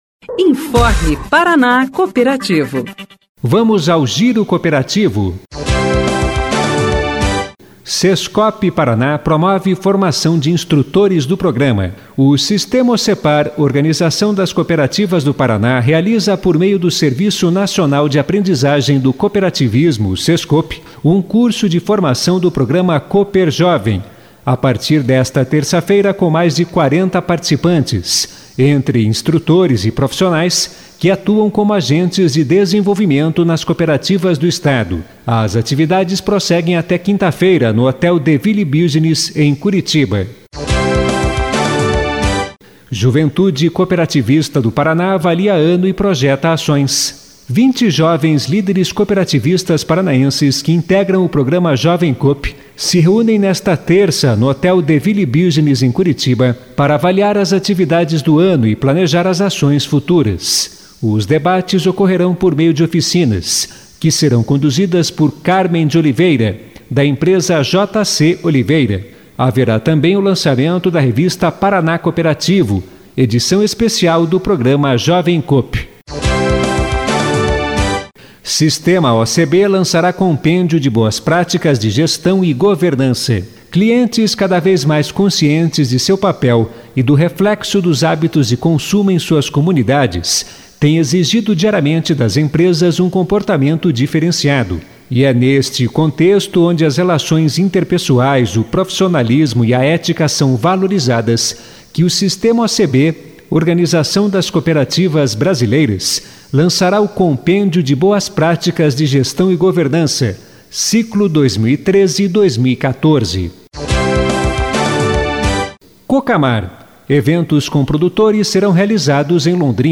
Notícias Rádio Paraná Cooperativo